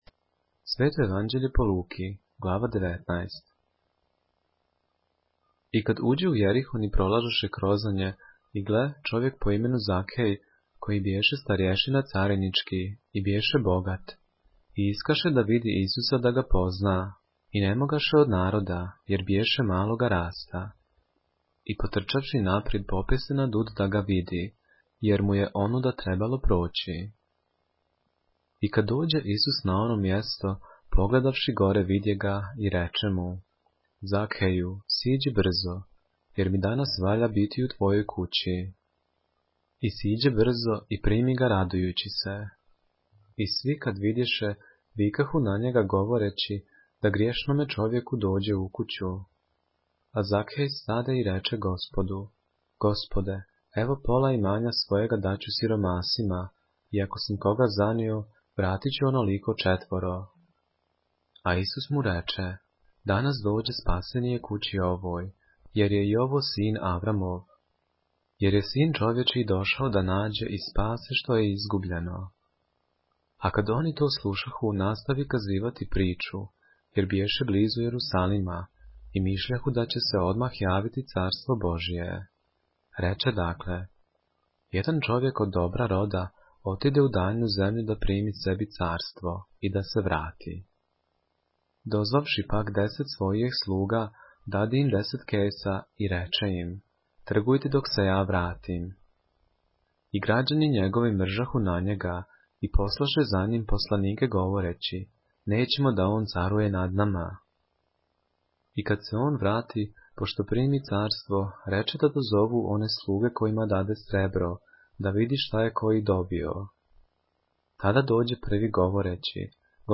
поглавље српске Библије - са аудио нарације - Luke, chapter 19 of the Holy Bible in the Serbian language